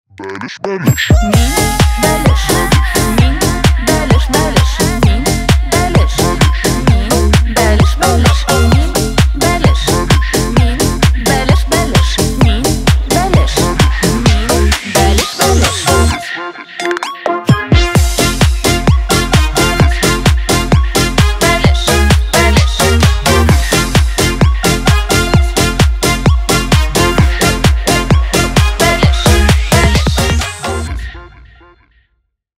Поп Музыка
весёлые # клубные